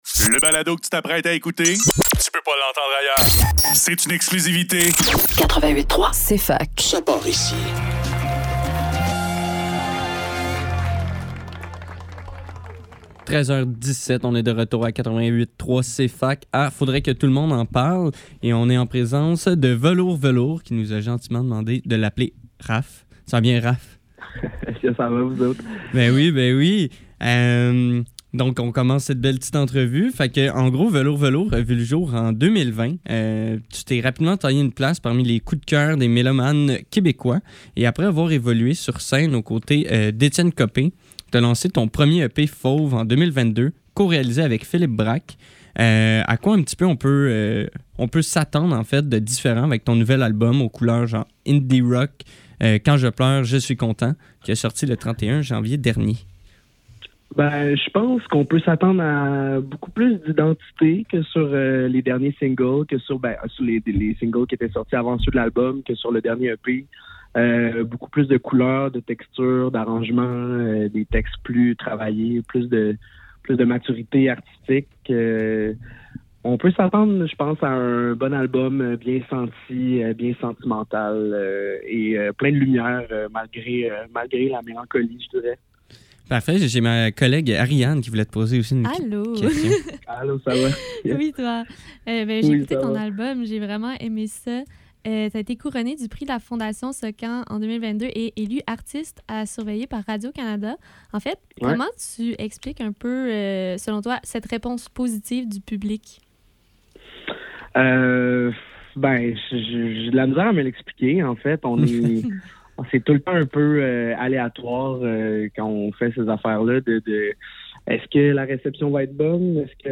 Faudrait que tout l'monde en parle - Entrevue avec Velours velours - 04 février 2025